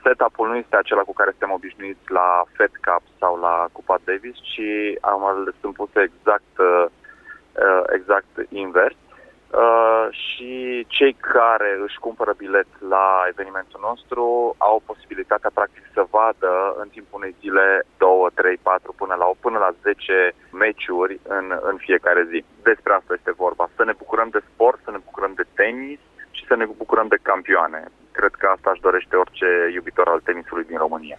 invitat în această dimineață la Radio Cluj